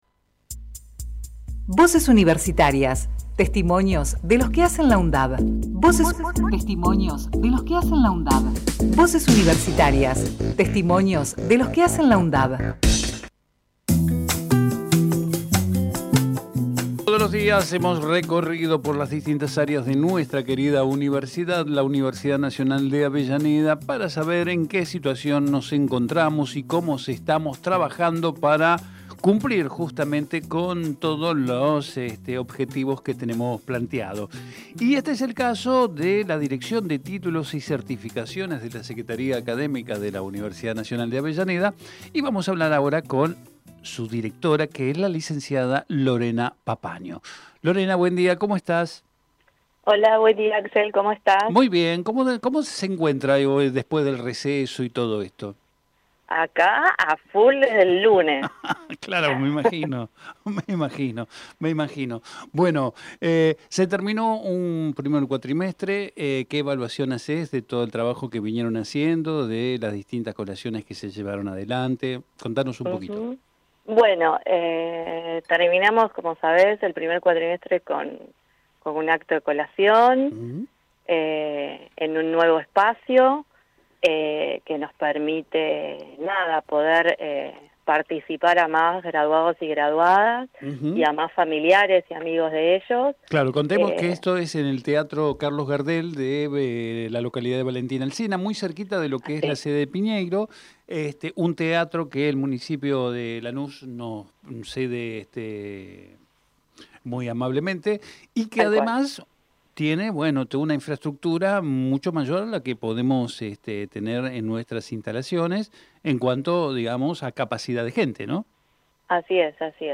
Compartimos la entrevista realizada en "Territorio Sur"